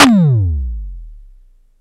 SIMMONS SDS7 11.wav